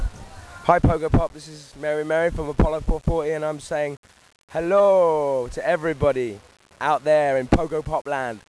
Roma, Tor di Valle, 28th june 2000 il saluto degli Apollo 440 agli ascoltatori di Pogopop